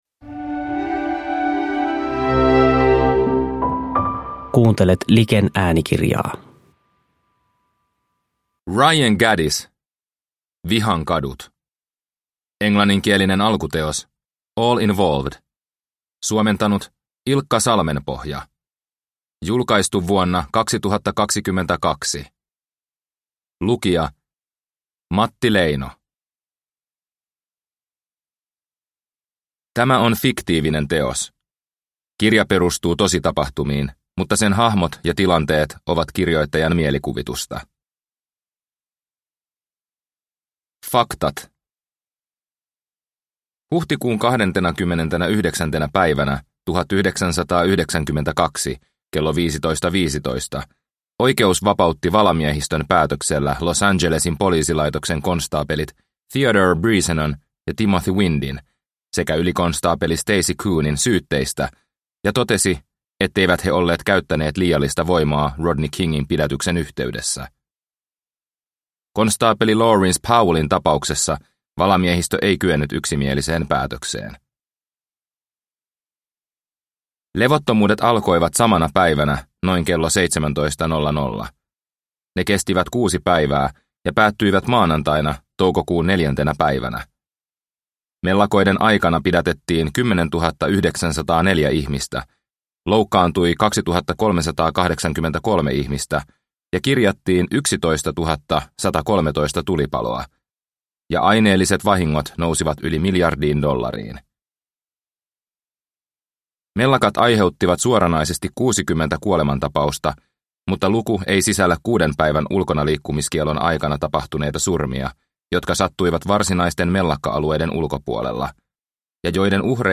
Vihan kadut – Ljudbok – Laddas ner